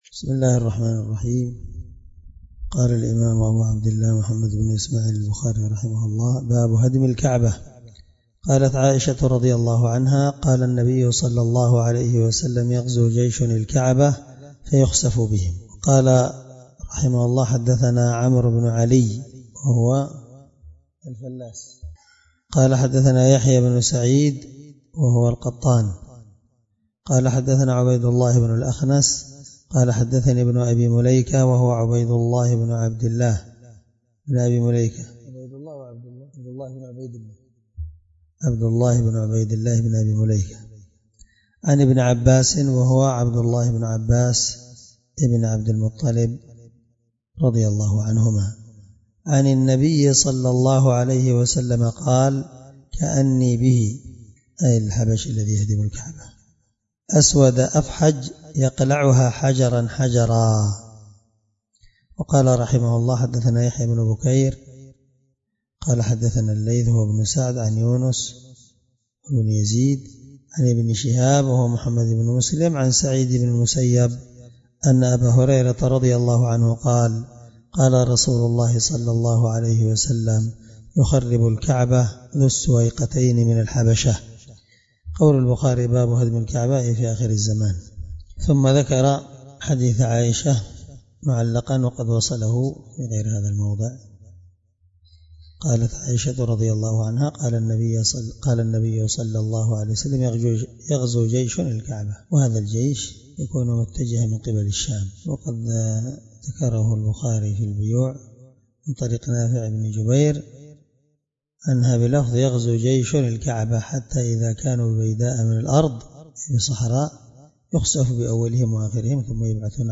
الدرس34 من شرح كتاب الحج حديث رقم(1595-1596 )من صحيح البخاري